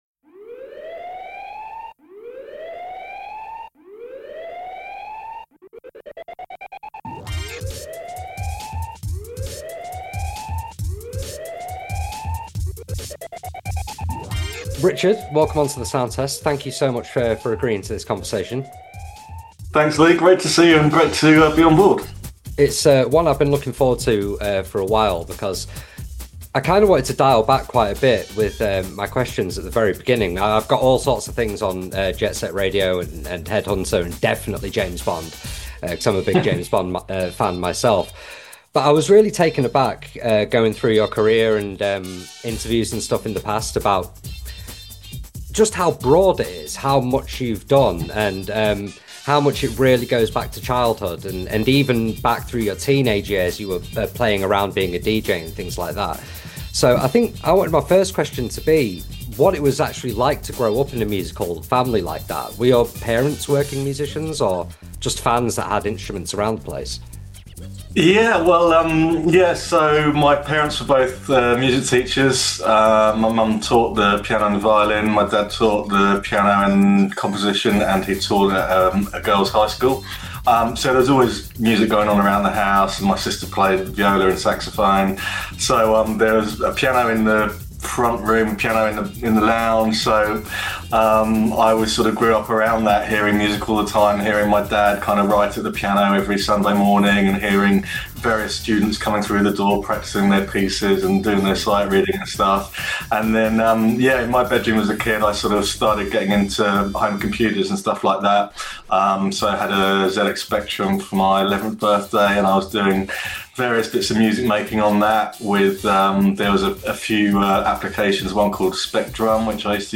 A conversation about all things game music with composer, Richard Jacques!